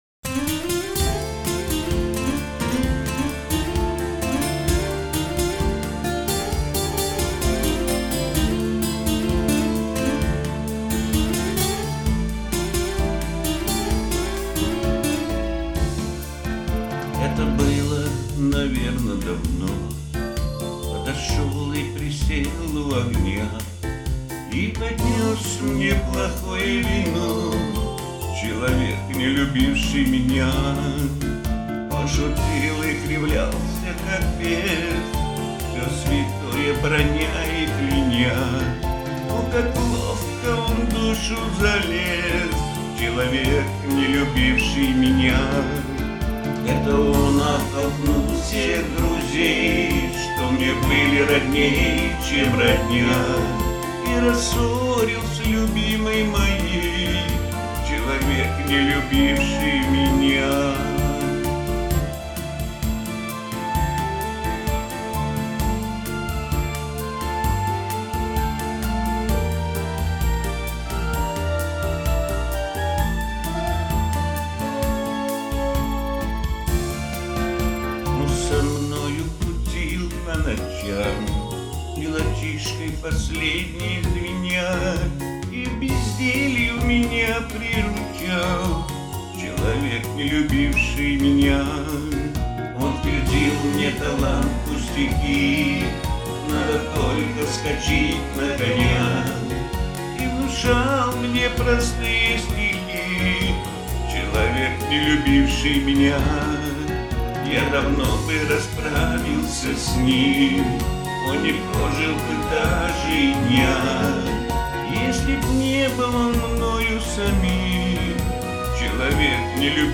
Её и поём Комментарий соперника: Сделал свою аранжировку.